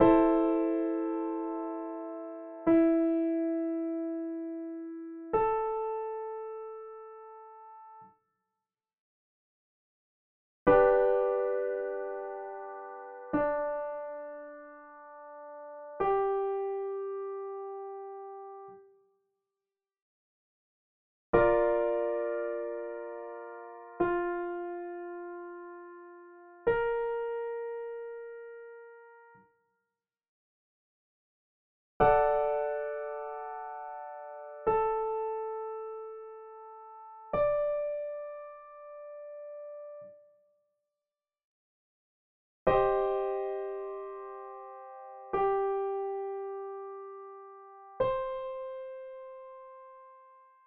The following example example give you a few second inversion triads highlighting the fourth between the lower members:
You might hear that second inversion triad has a slightly greater sense of instability than root and first inversion ones.